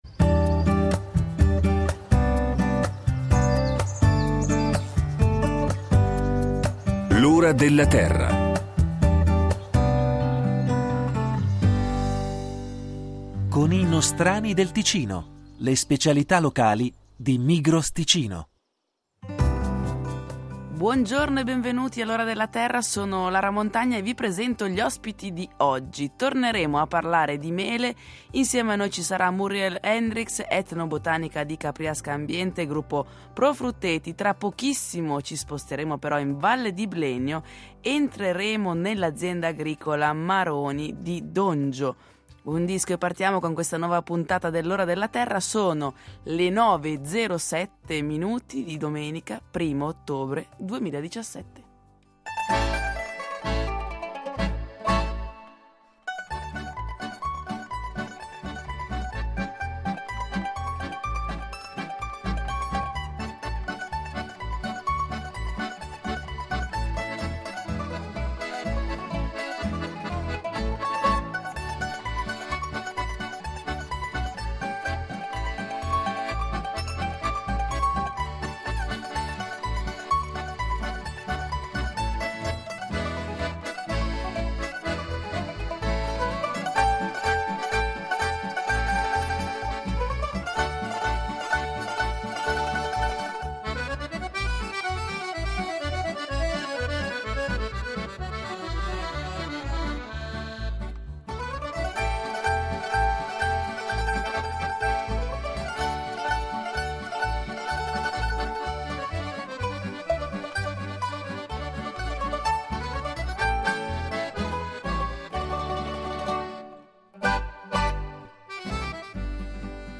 I tre esperti de L’Ora della Terra sono in studio per rispondere alle domande del pubblico da casa.